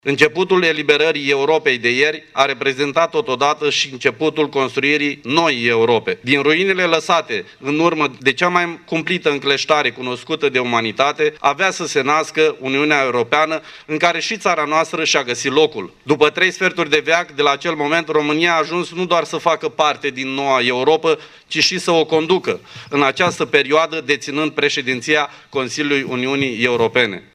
Autorităţi locale şi judeţene din Iaşi au marcat, astăzi, Ziua Eroilor, la monumentul din Cimitirul Eternitatea, la cel din dealul Galata şi la cimitirul eroilor căzuţi în al Doilea Război Mondial de la Leţcani.
Pe de altă parte, Marian Şerbescu a amintit că astăzi se împlinesc 75 de ani de la Debarcarea aliaţilor în Normandia: